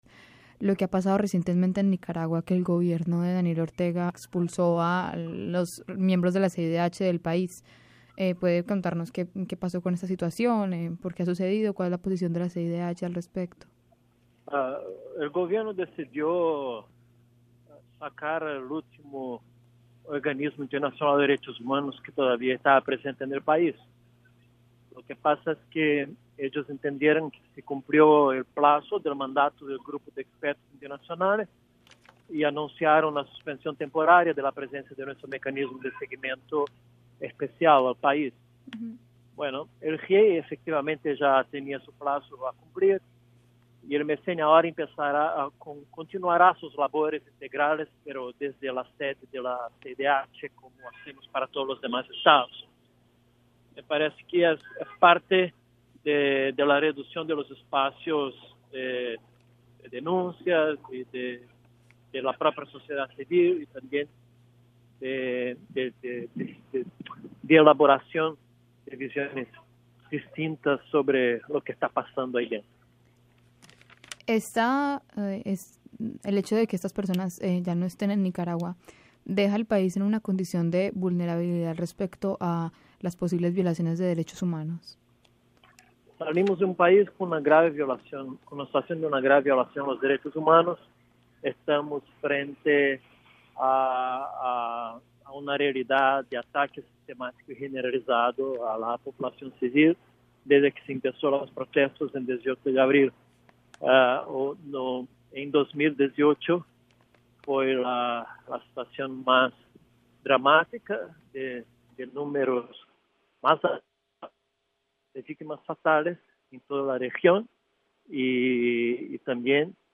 Entrevista a Paulo Abrao, director ejecutivo de la CIDH